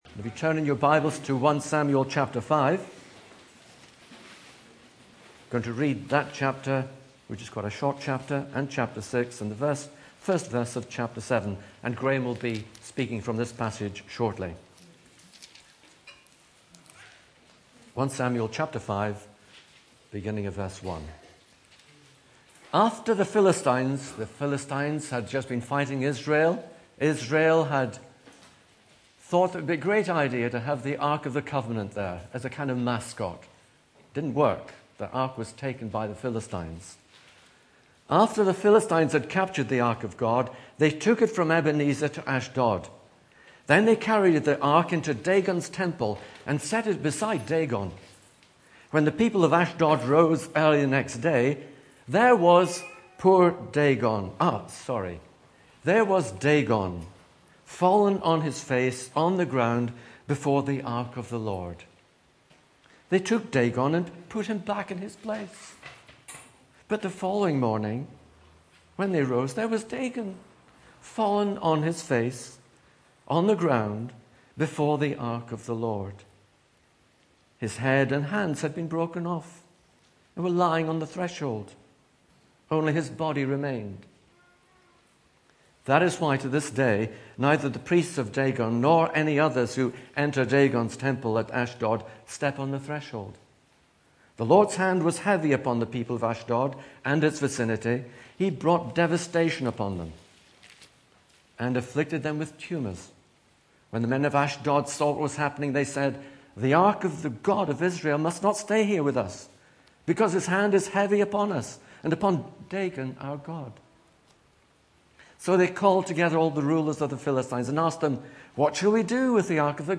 Back to Sermons God is not contained or constrained